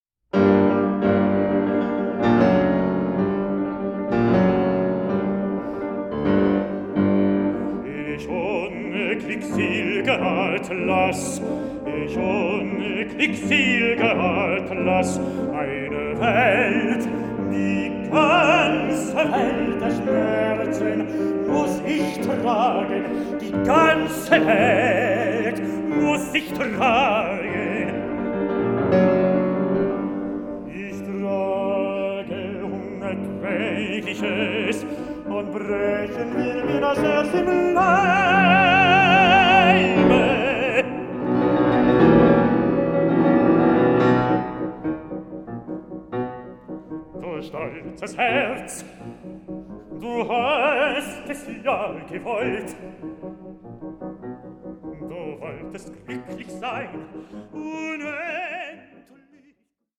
(48/24, 88/24, 96/24) Stereo  14,99 Select